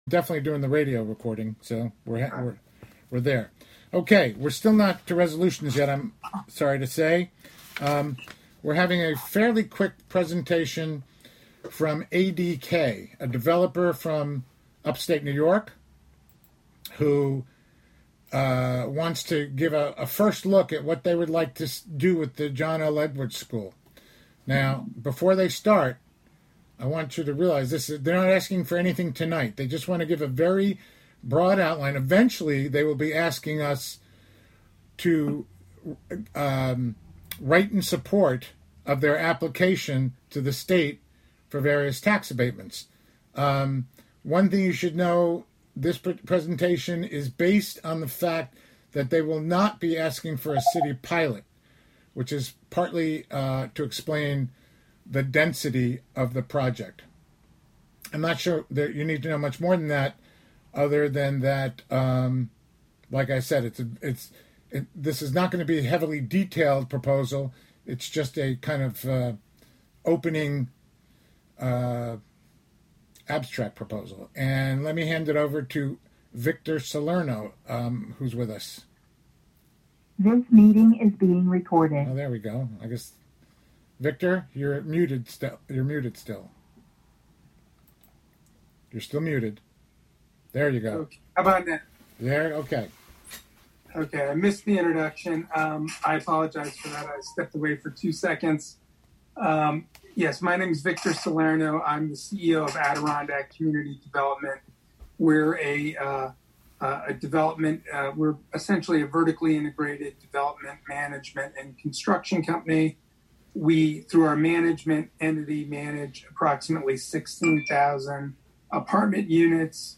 Live from the City of Hudson: Hudson Common Council Formal Meeting (Audio)